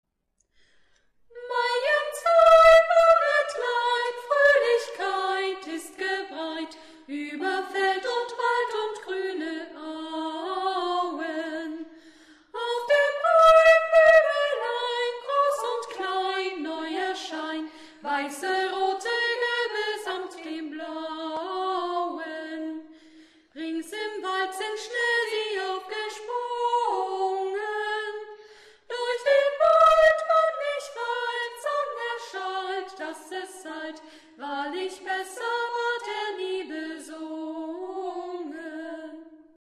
Dieses Lied wurde vom Sextett der Freien Waldorfschule Halle eingesungen.